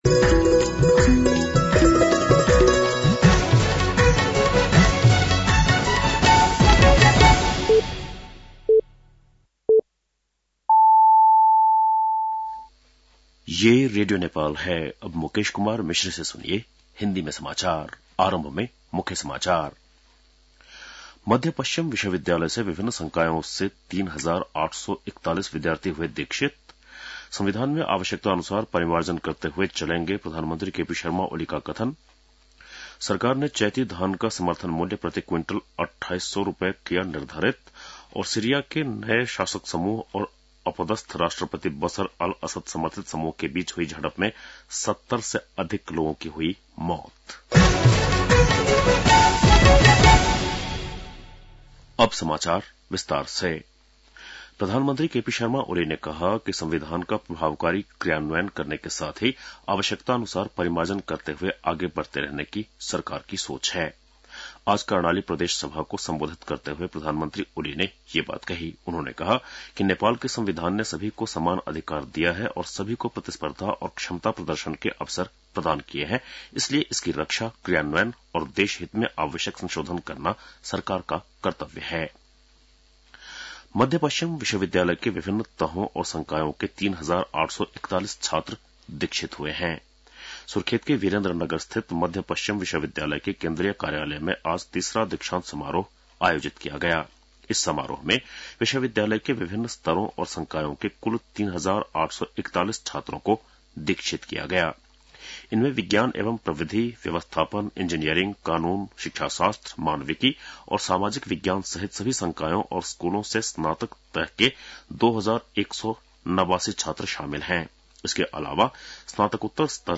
बेलुकी १० बजेको हिन्दी समाचार : २४ फागुन , २०८१